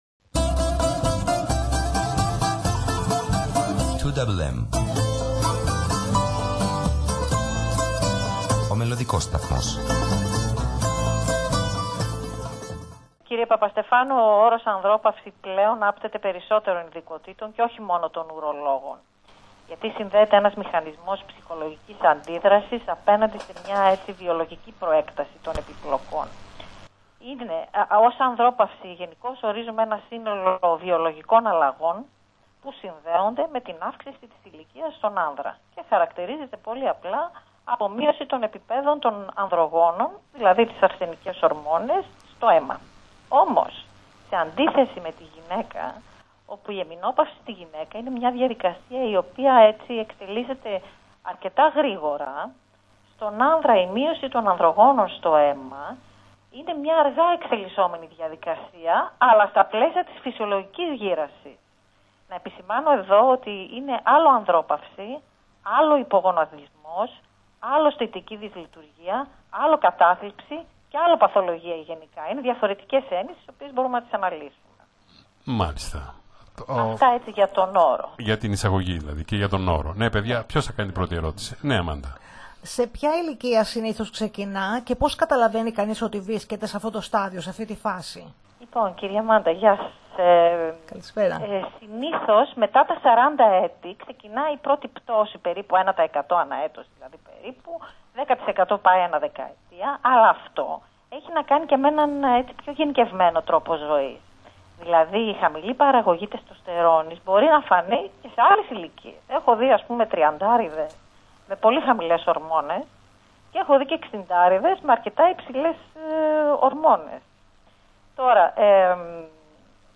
στην διάρκεια της ζωντανής συνομιλίας